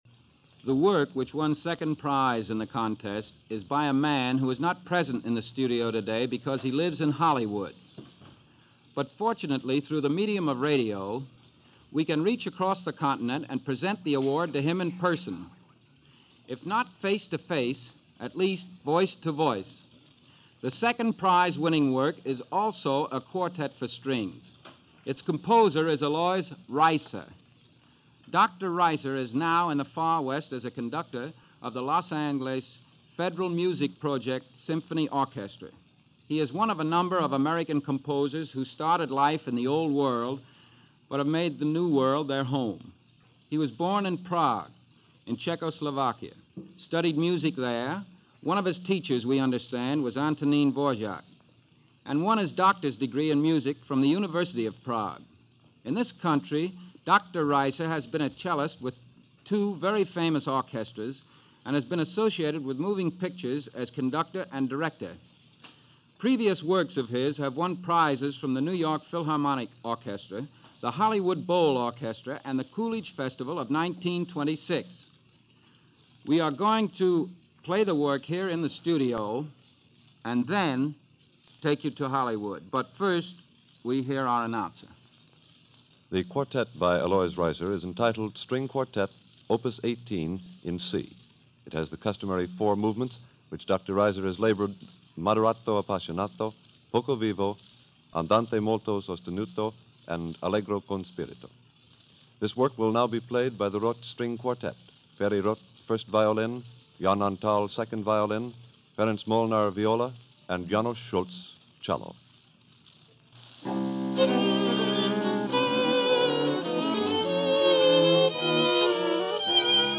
String Quartet op. 18
The 1936 Music Guild Awards, as broadcast on January 1, 1937. This is the second of three programs where the winners were announced and their compositions played for the first time to a network radio audience.